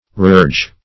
reurge - definition of reurge - synonyms, pronunciation, spelling from Free Dictionary Search Result for " reurge" : The Collaborative International Dictionary of English v.0.48: Reurge \Re*urge"\, v. t. To urge again.